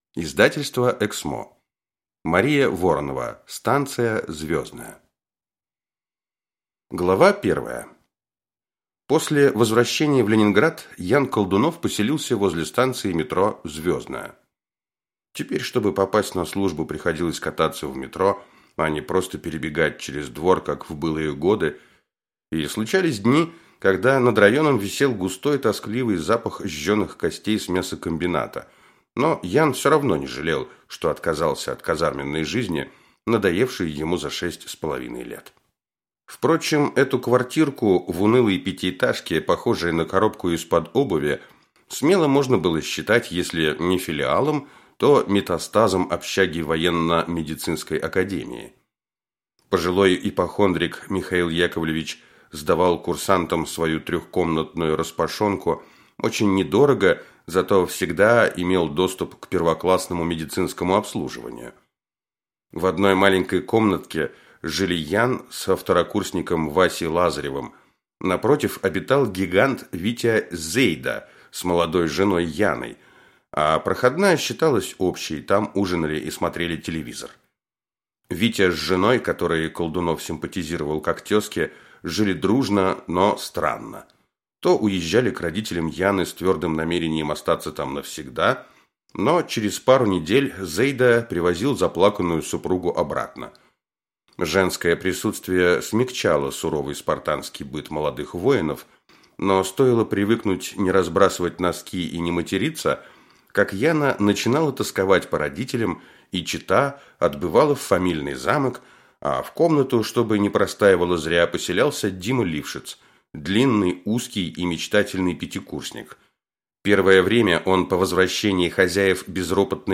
Аудиокнига Станция «Звездная» | Библиотека аудиокниг